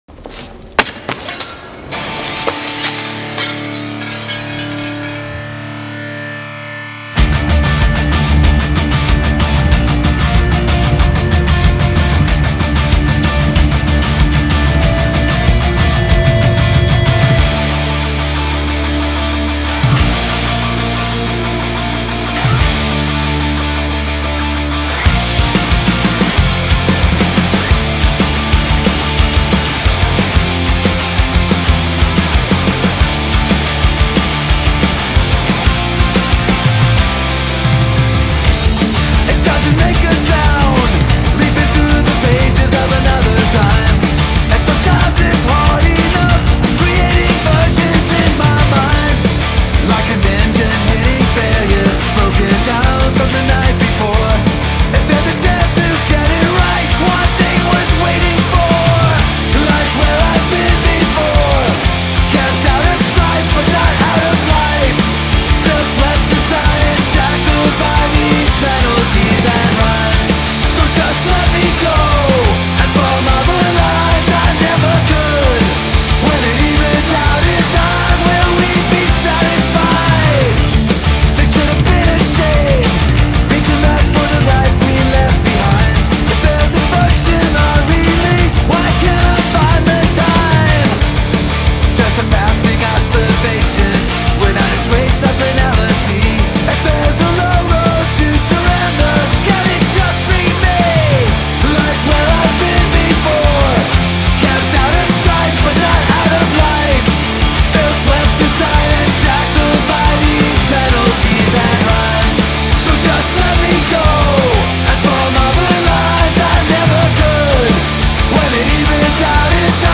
Punk/Hardcore